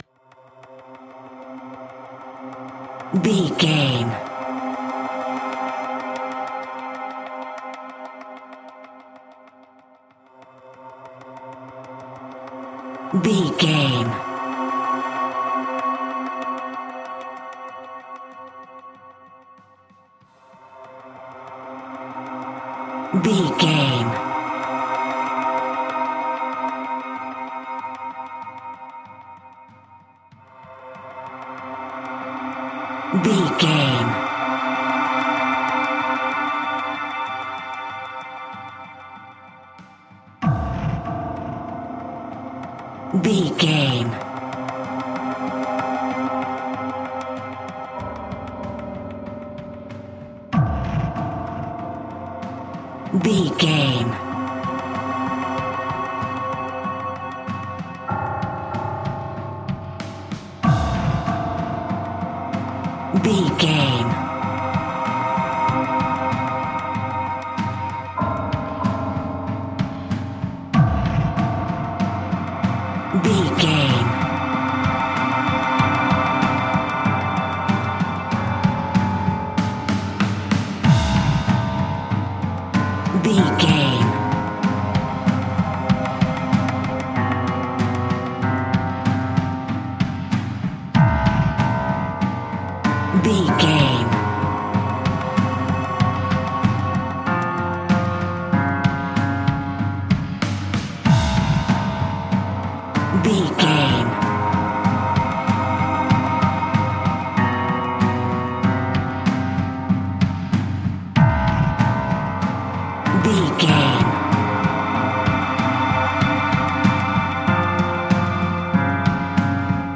Thriller
Aeolian/Minor
ominous
haunting
eerie
synthesiser
percussion
strings
piano
horror music
Horror Pads